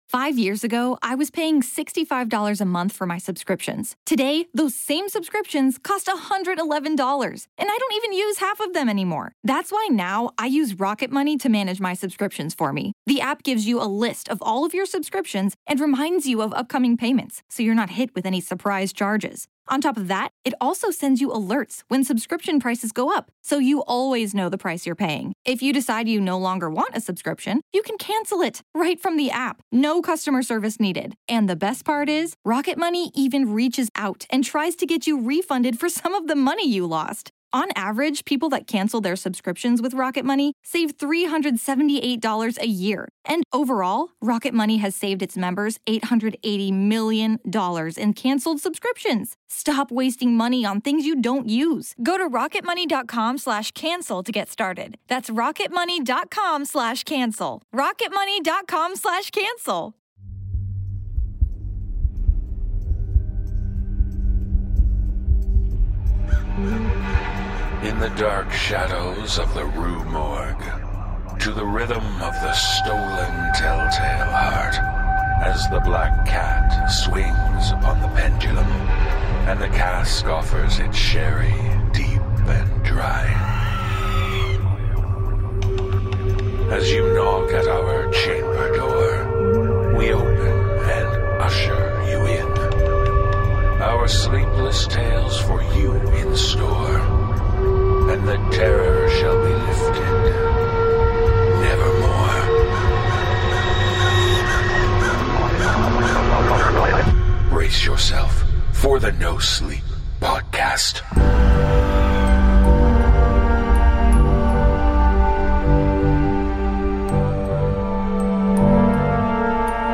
Cast: Narrator